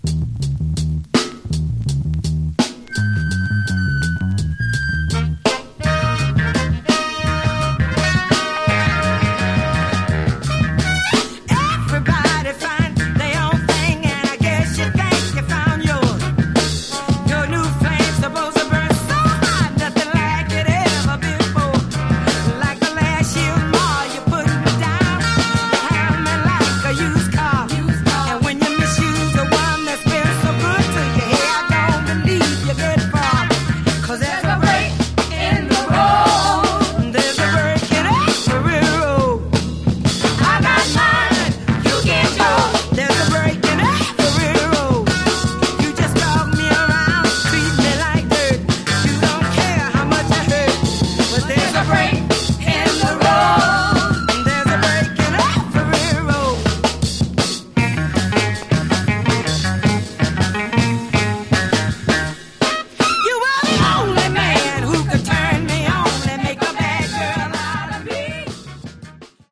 Genre: Funky Soul